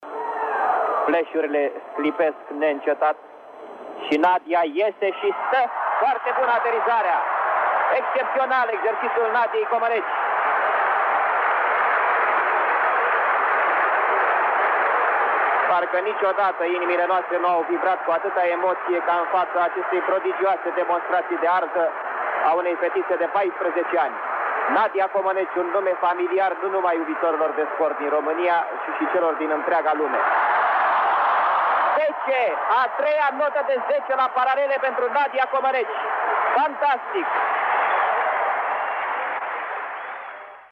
Vă propunem să reascultaţi unul dintre cele mai cunoscute comentarii ale lui Cristian Ţopescu, concursul de gimanstică de la Oliampiada de Vară de la Montreal din 1976, când Nadia Comăneci a luat primele note de zece din istoria gimnasticii: